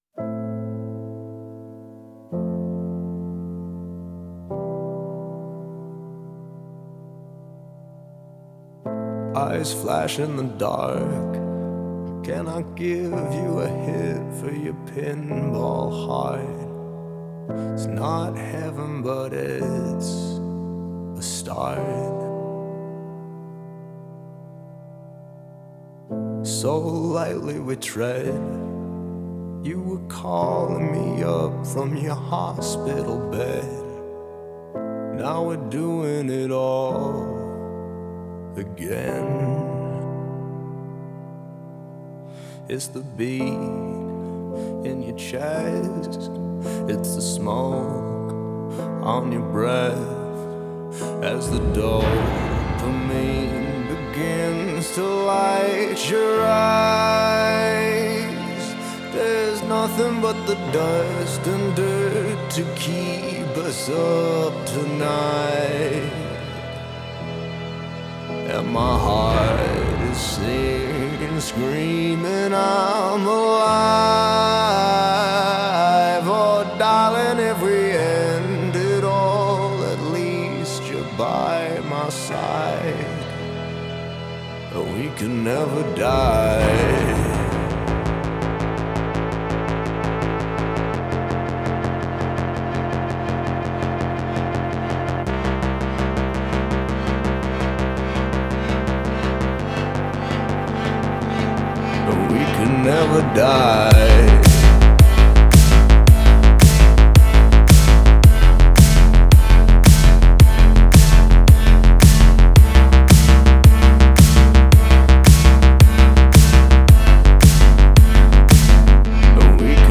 دانلود آهنگ راک لایت